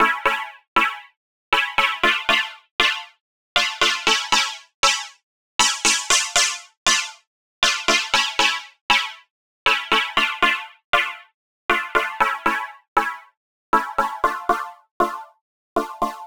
• Essentials Key Pop 5 118 bpm.wav
SC_Essentials_Key_Pop_5_118_bpm_cBr_Rnb.wav